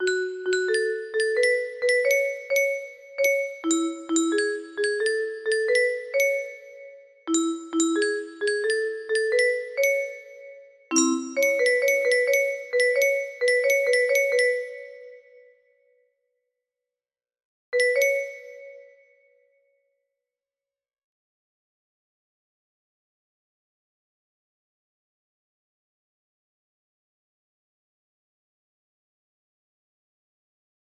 41-44 music box melody